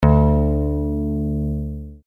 Low D String